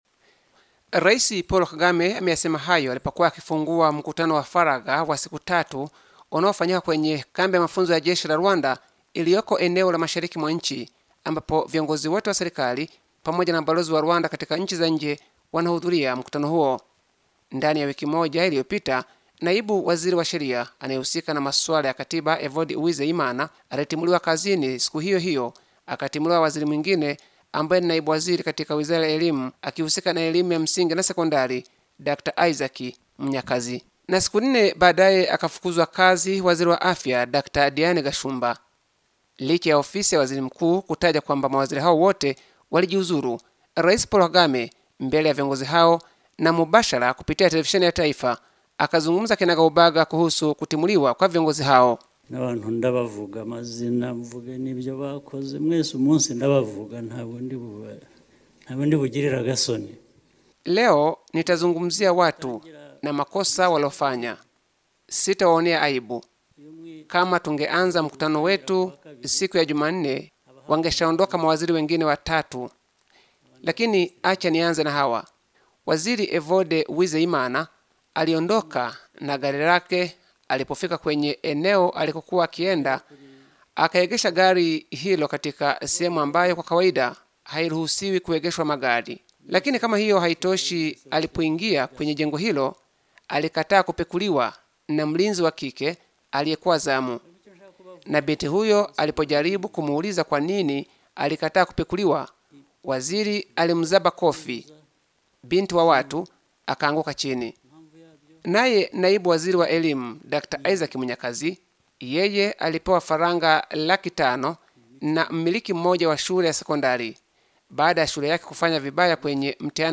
Ndani ya wiki moja tu Rais Paul Kagame amewafuta mawaziri watatu kutokana na makosa ya ulaji rushwa, udanganyifu pamoja na ukosefu wa nidhamu mbele ya wananchi. Kutoka Kigali mwandishi wetu